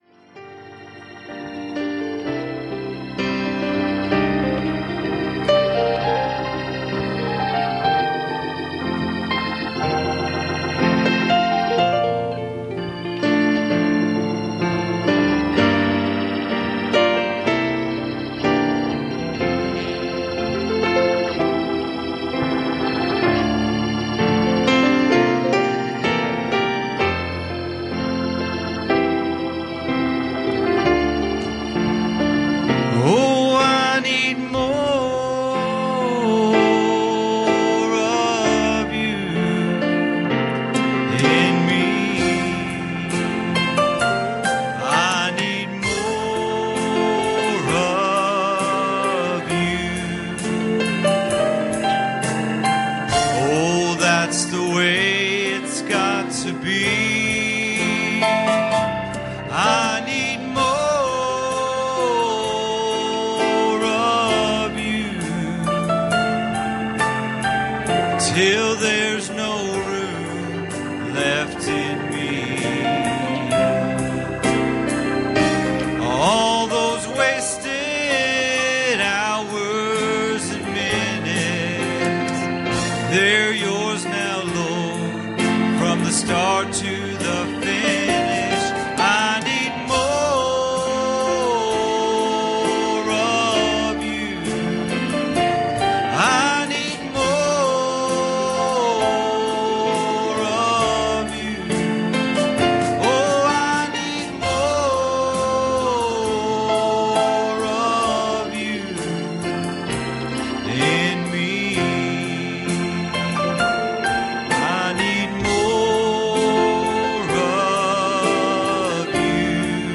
Passage: Luke 19:37 Service Type: Wednesday Evening